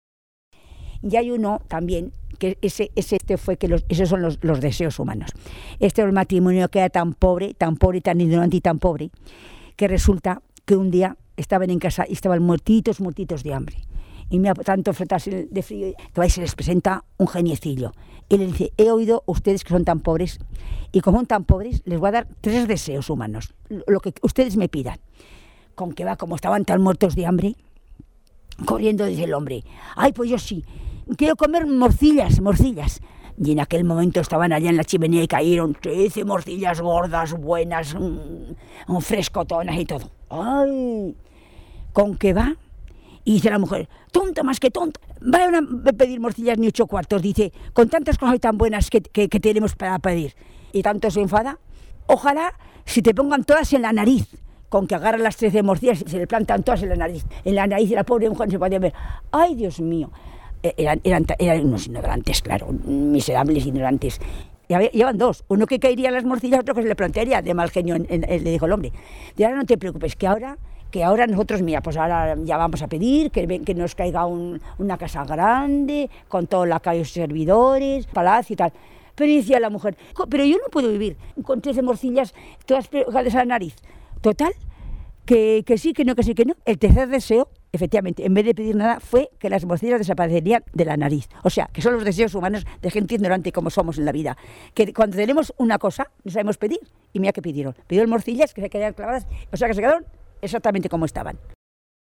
Clasificación: Cuentos
Lugar y fecha de recogida: El Redal, 17 de julio de 2003